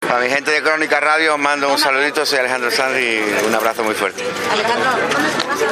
SALUDO_ALEJANDRO_SANZ_A_CRONICAS_RADIO.mp3